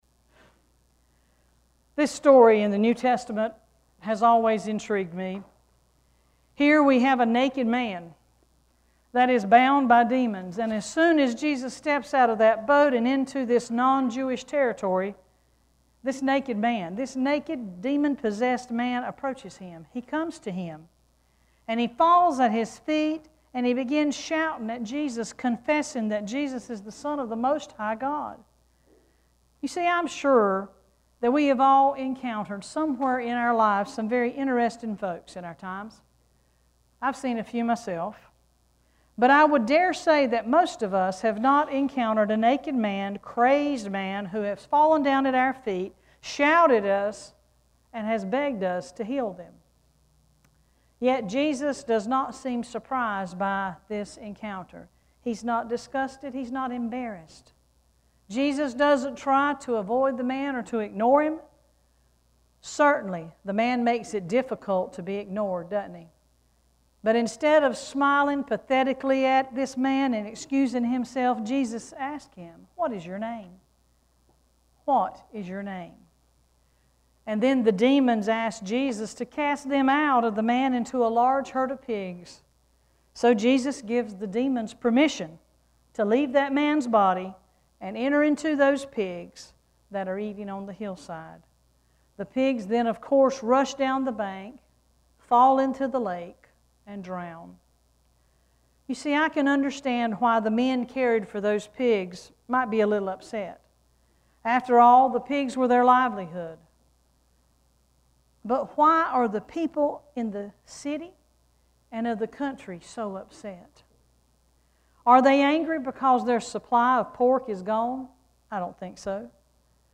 6-19-sermon.mp3